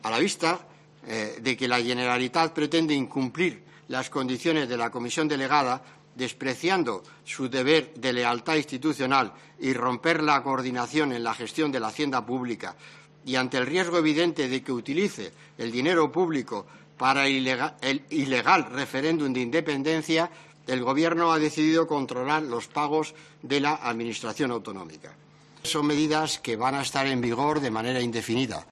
Ha sido al inicio de su comparecencia ante la Comisión de Hacienda del Congreso, para explicar las medidas que el Gobierno acordó el viernes pasado para intervenir las cuentas de Cataluña.